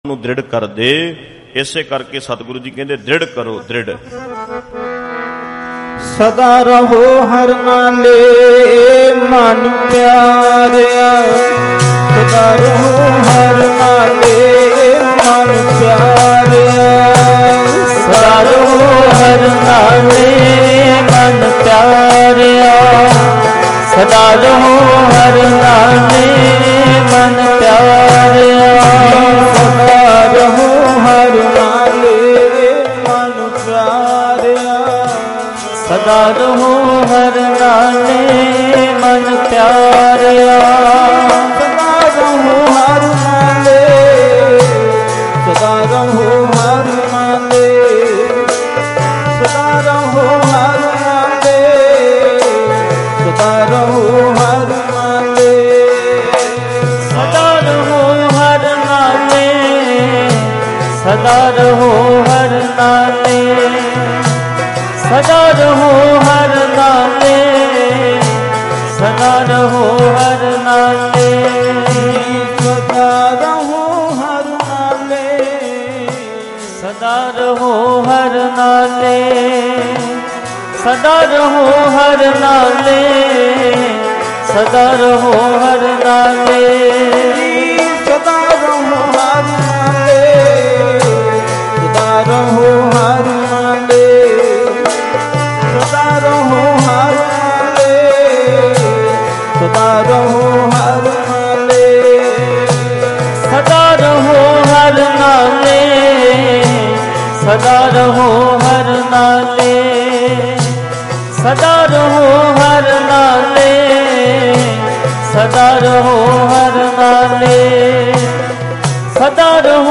Live Gurmat Samagam Narangwal Ludhiana 22 Aug 2025